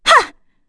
FreyB-Vox_Attack2.wav